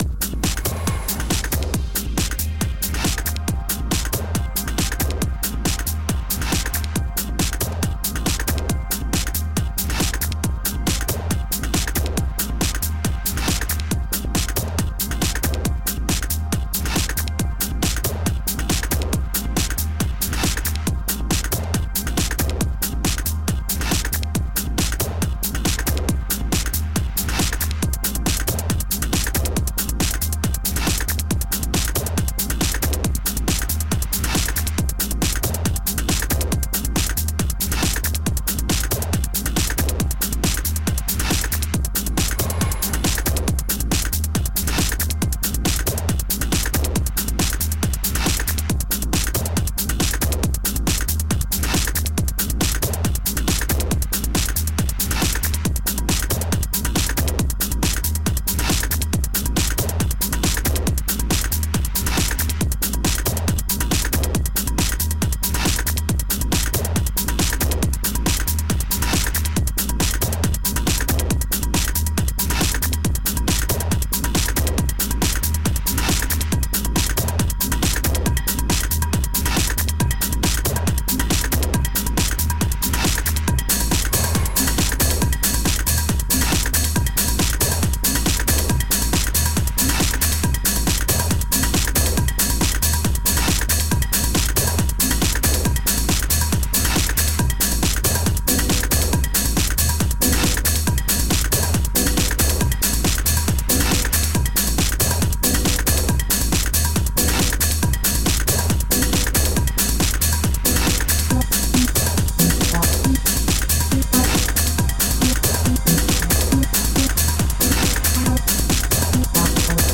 Tech-Trance Track: Shifting Bells
A book by Mark Butler prompted me to put together an experiment in shifting grooves back and forth across the beat.
If you listen to the bells, they shift throughout the track both in terms of their texture and in terms of their timing.